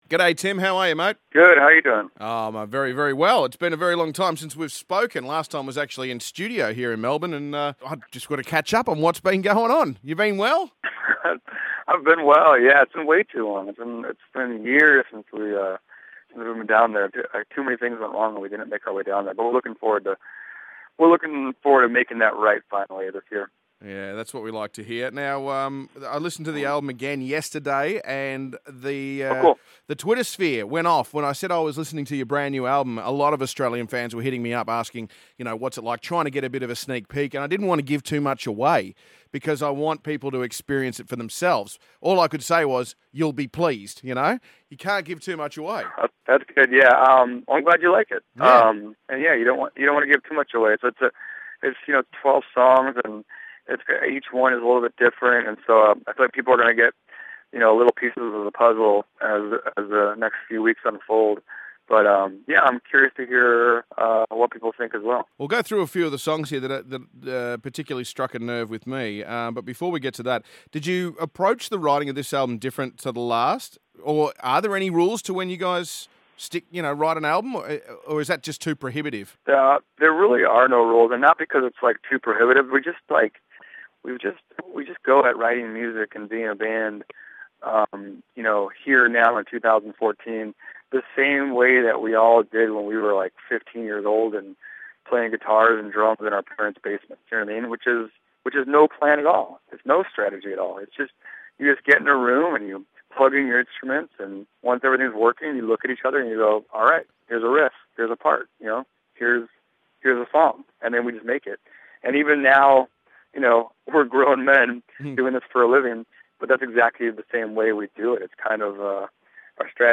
RISE AGAINST INTERVIEW June 26th 2014
I spoke to lead singer Tim McIlrath about the new Rise Against album The Black Market which is set for release on July 15. We speak about his songwriting, influences, responsibilities and touring Australia.